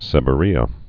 (sĕbə-rēə)